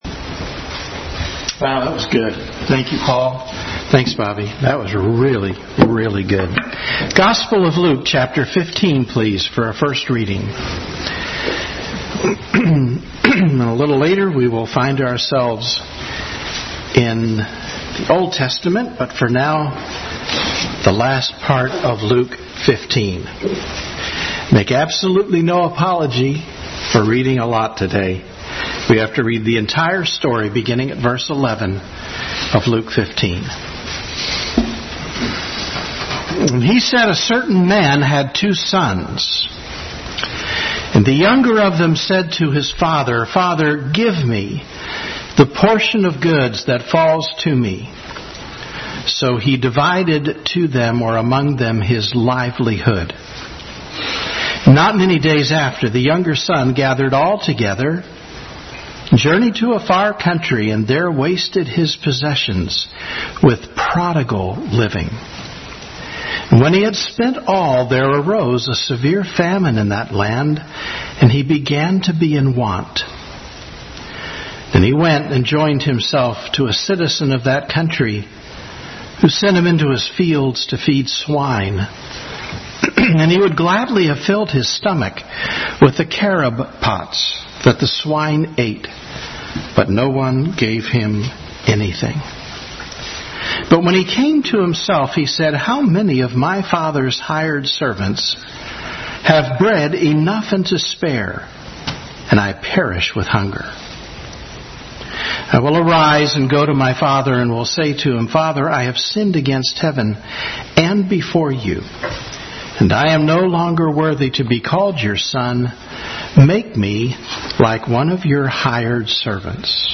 Bible Text: Luke 15:11-32, Deuteronomy 21:18-21 | Family Bible Hour message – The Prodigal Son.